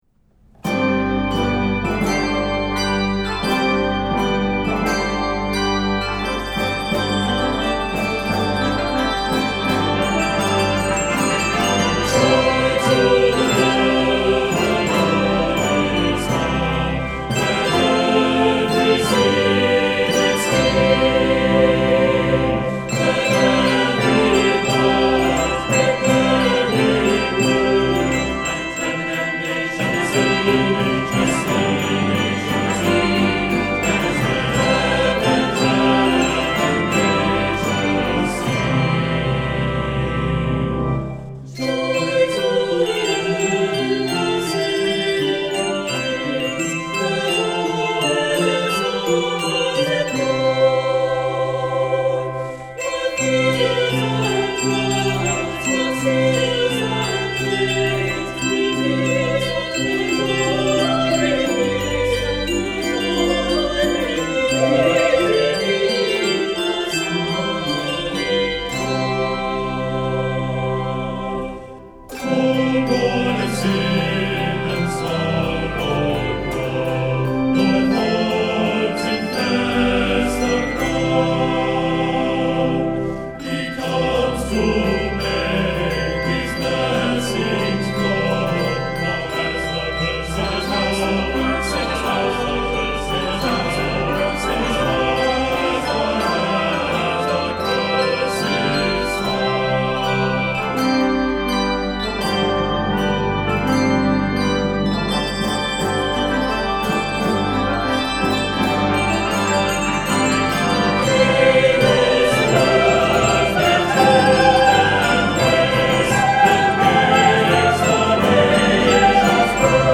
1st B-flat Trumpet
B-flat Tenor Saxophone
1st Trombone
Guitar
String Bass
Drums